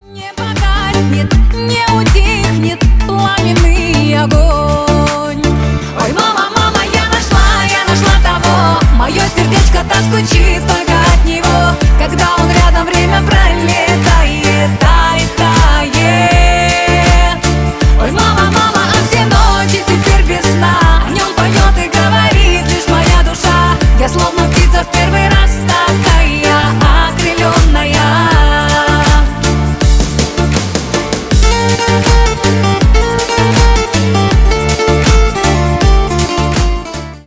кавказские
поп